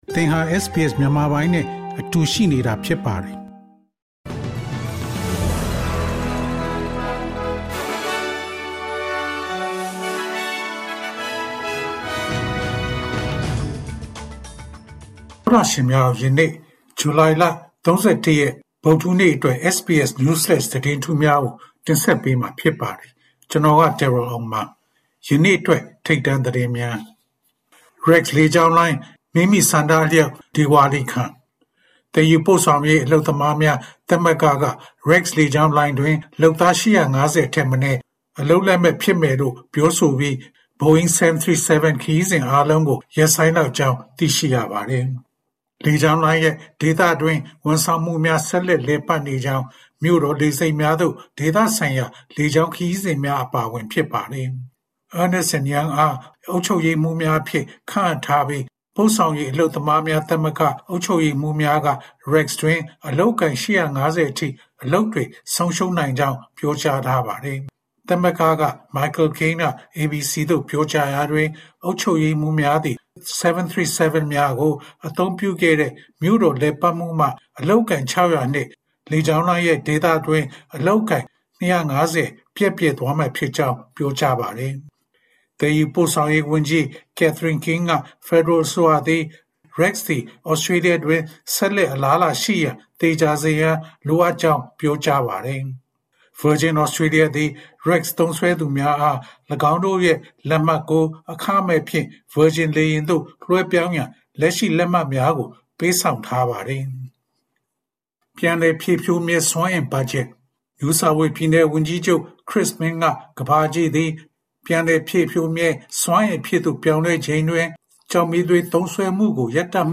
ဇူလိုင်လ ၃၁ ရက် တနင်္လာနေ့ SBS Burmese News Flash သတင်းများ။